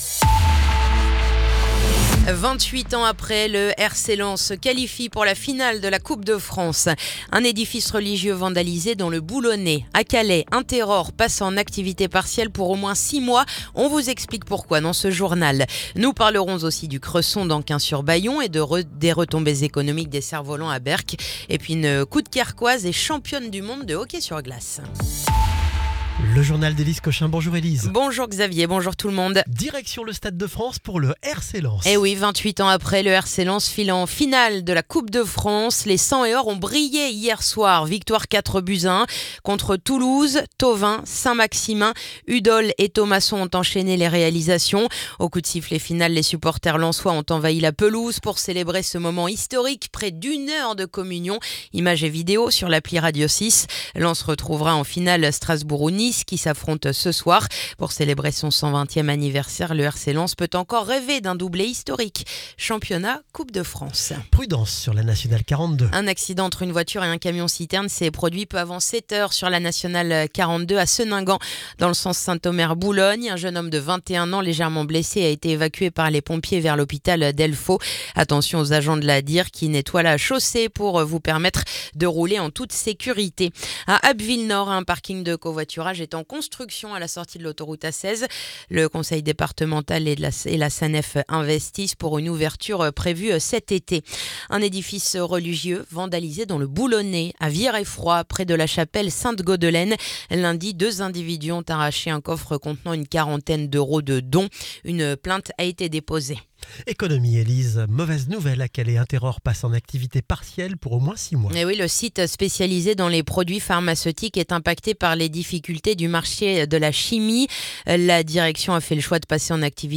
Le journal du mercredi 22 avril